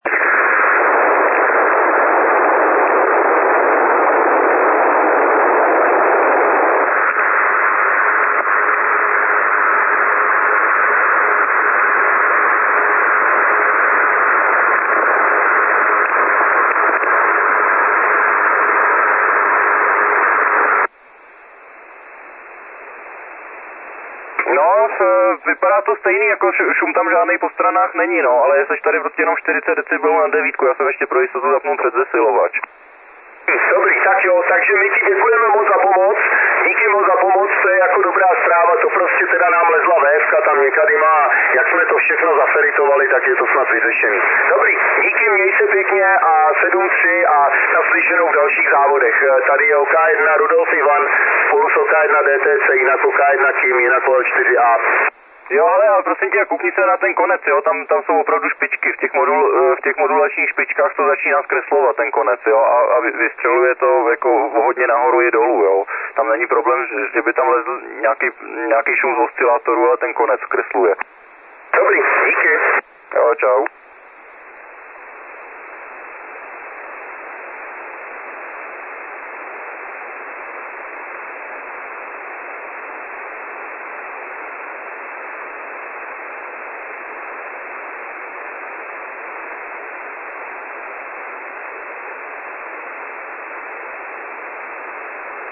Asi ve 2 MHz segmentu slyším příšerné chroustání od OL4A a stanice, které jsou ode mne vzdušnou čárou 80-100 km musím několikráte prosit o zopakování předávaného kódu.
rušení OL4A_1.mp3 (382 kB)